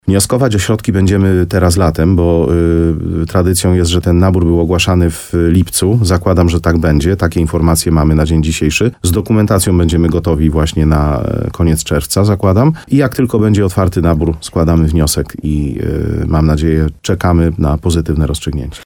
Jak mówi wójt gminy Łużna Mariusz Tarsa, chociaż jest to inwestycje na kolejne lata, to właśnie najbliższe miesiące mogą zadecydować o szybkości jej powstania.